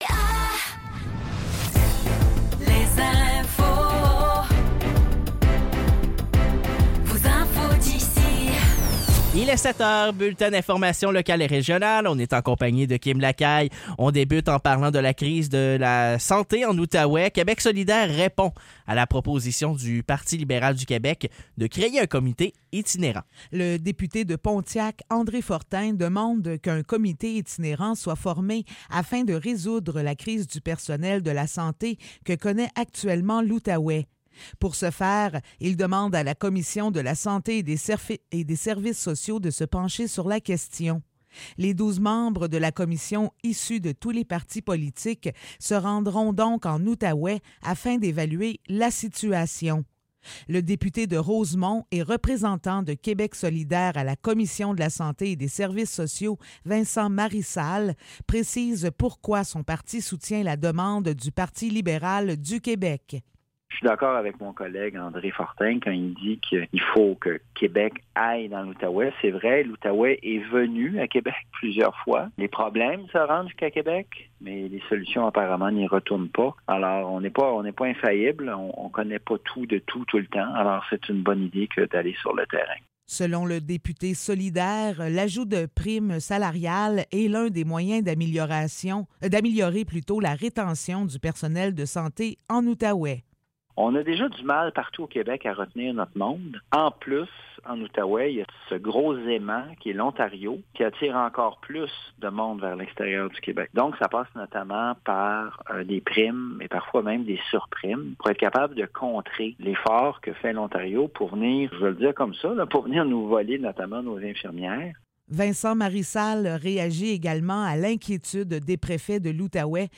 Nouvelles locales - 14 mai 2024 - 7 h